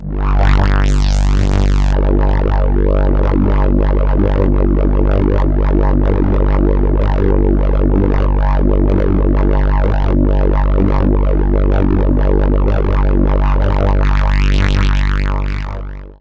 55-DIDJERI-R.wav